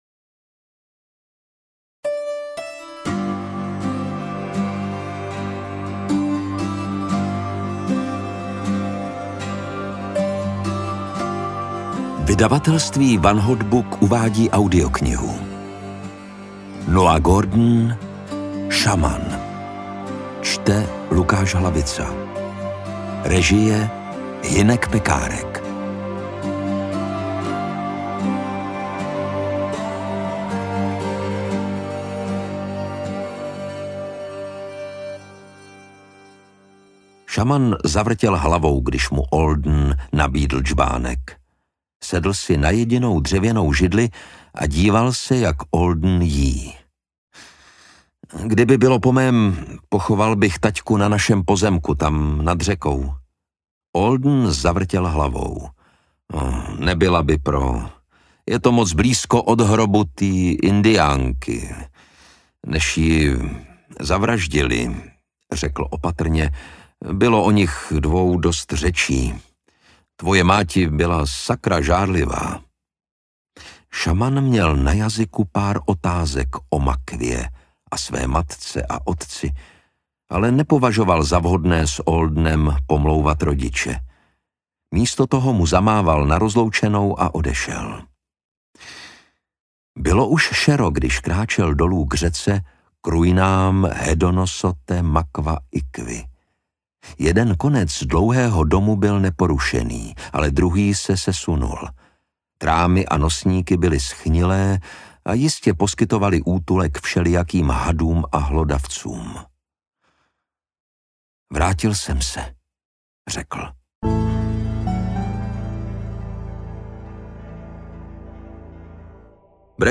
Šaman audiokniha
Ukázka z knihy
• InterpretLukáš Hlavica